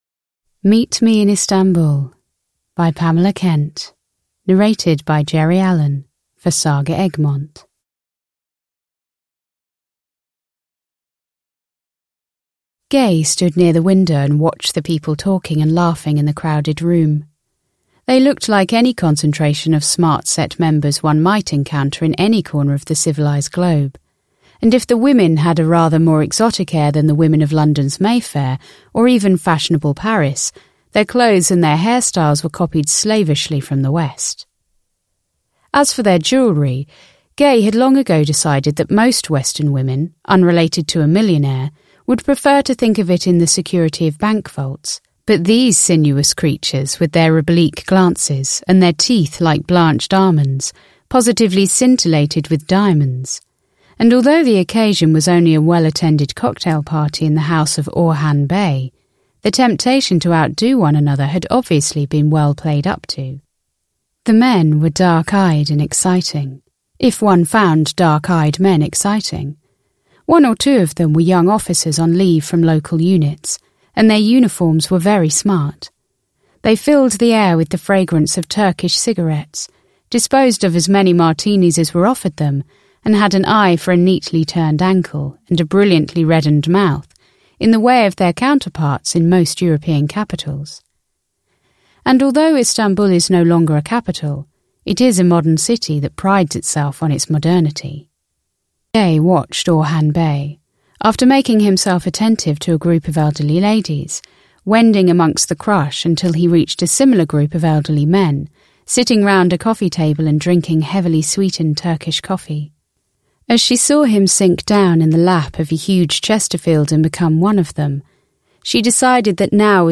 Meet me in Istanbul (ljudbok) av Pamela Kent